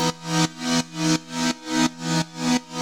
GnS_Pad-MiscA1:4_170-E.wav